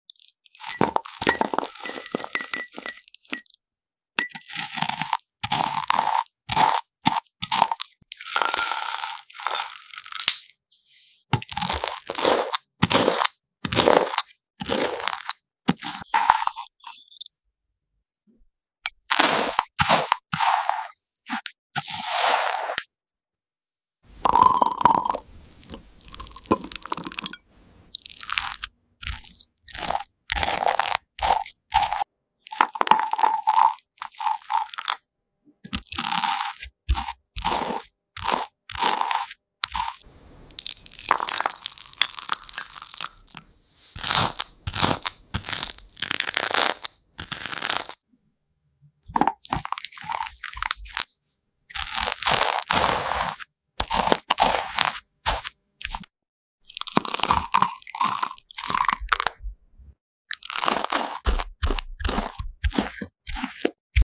Spreading fruit salad onto toast